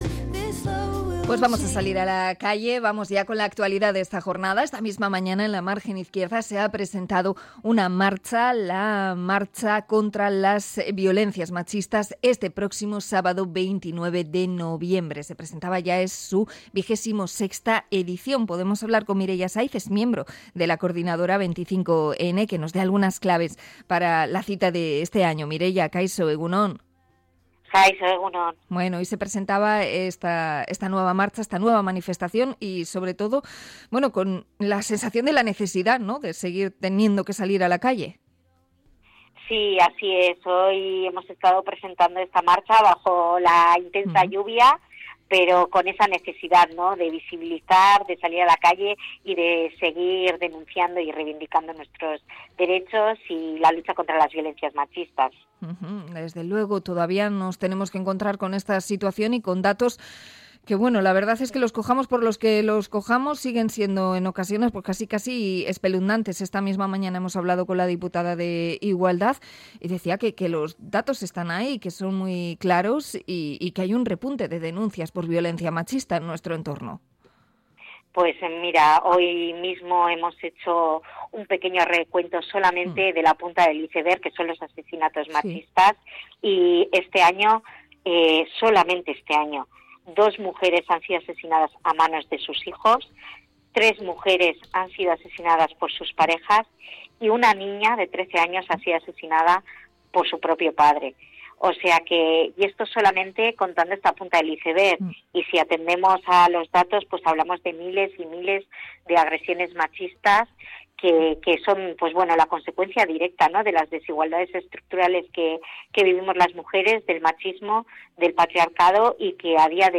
Entrevista con Argitan por la marcha del 25N